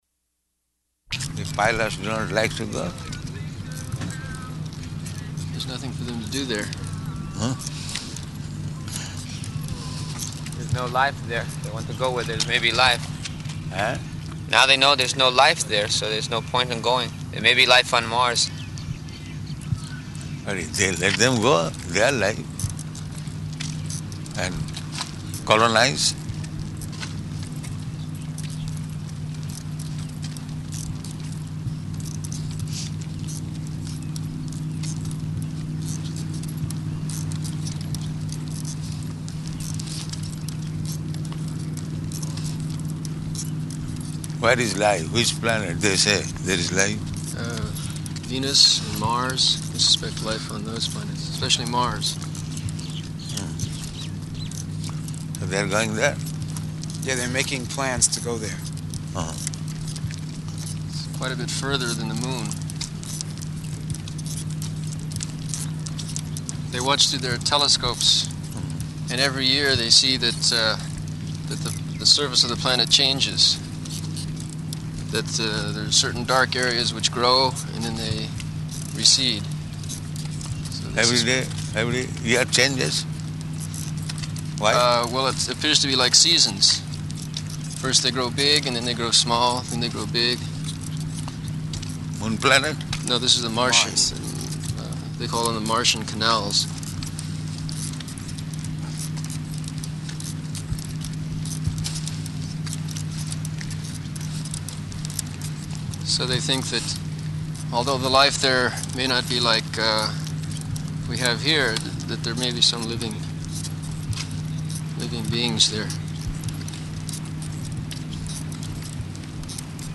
Morning Walk, partially recorded
Type: Walk
Location: Honolulu